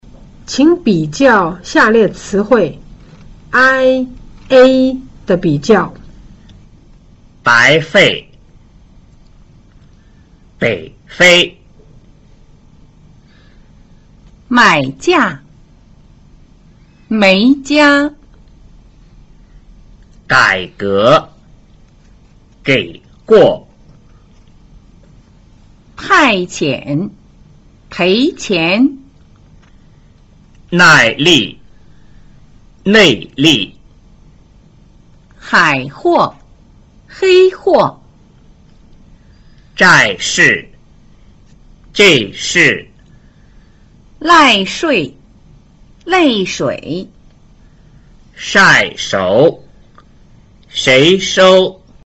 ai     唇形由開逐漸為閉, 不圓唇。
ei    口腔開度由半閉漸變為閉，唇形漸變為更扁。
ai – ei的比較